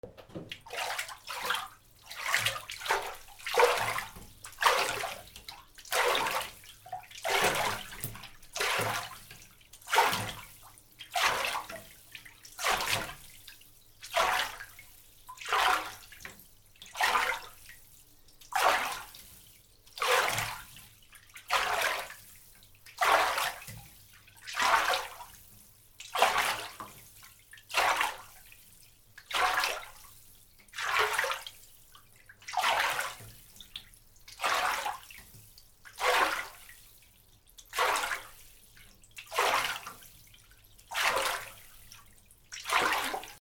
/ M｜他分類 / L30 ｜水音-その他
水音 ハンガーで水をかく
『パシャパシャ』